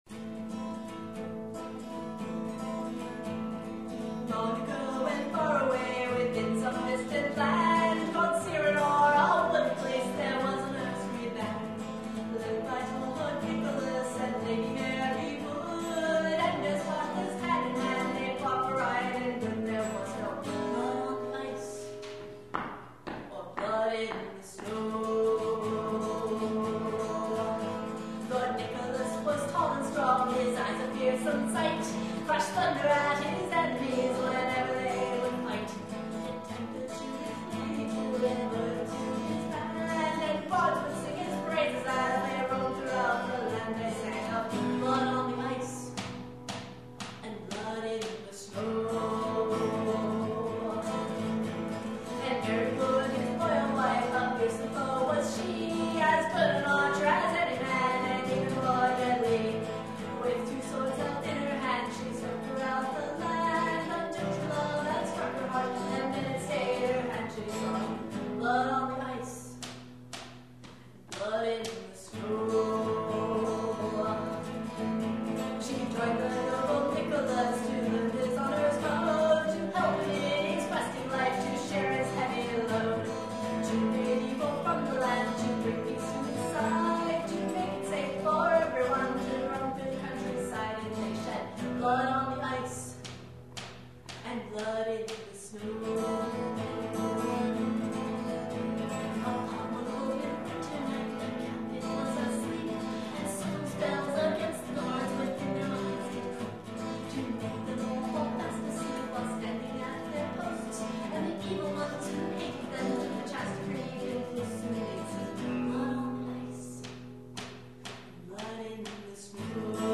The version attached to this post is a live version I sang for the concert.  Apologies for the poor sound quality.